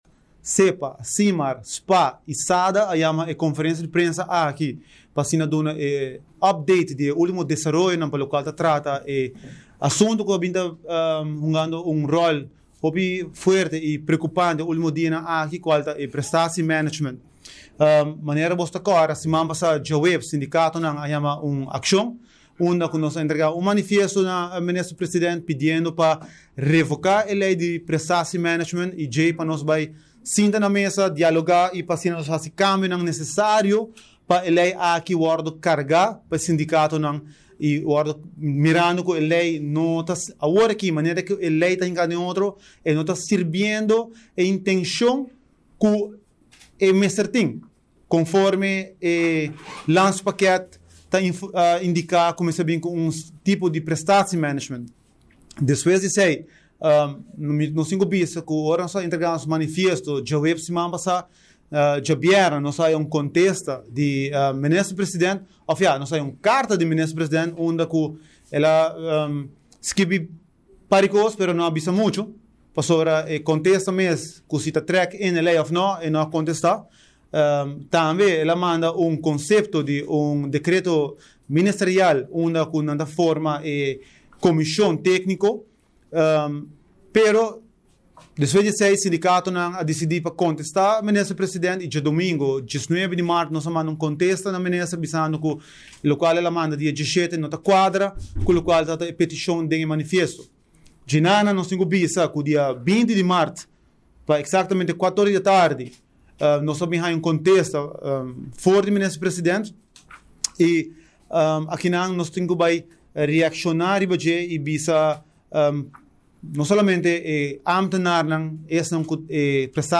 Bloke sindical a yama un conferencia di prensa.